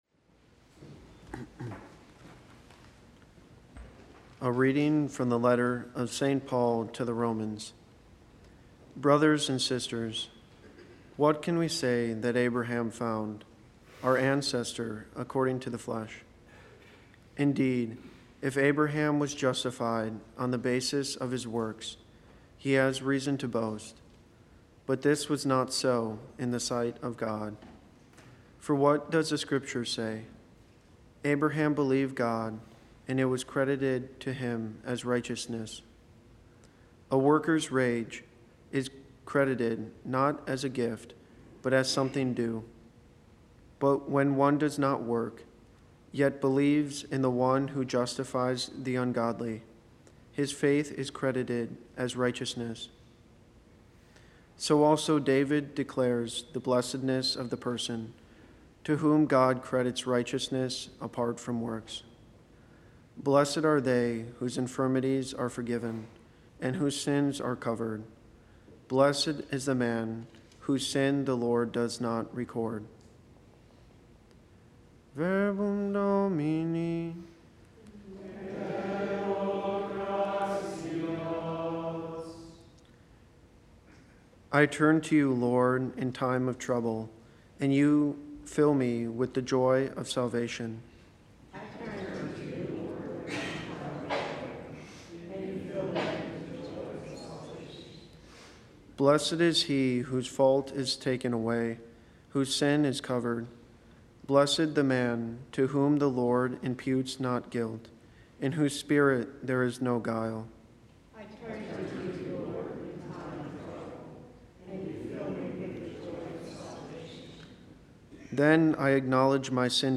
Readings, Homily and Daily Mass
From Our Lady of the Angels Chapel on the EWTN campus in Irondale, Alabama.